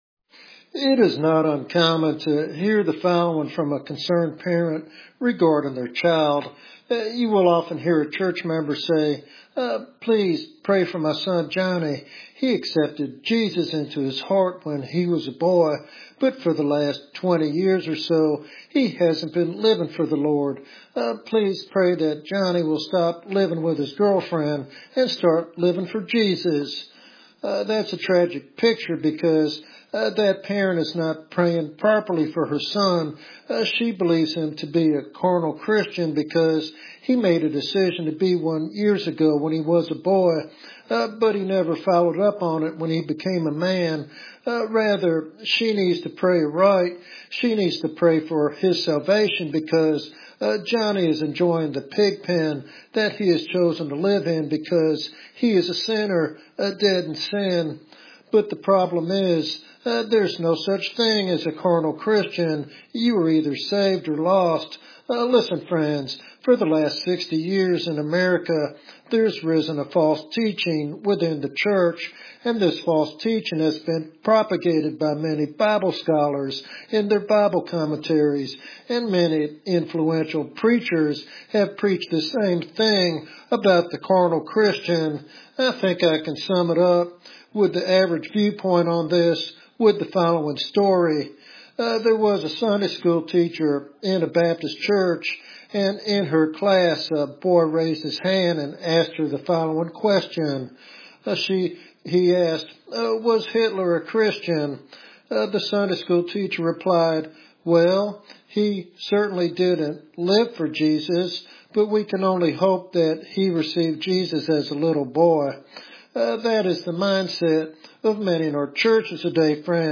This sermon challenges believers to examine their spiritual state and embrace the lordship of Christ fully.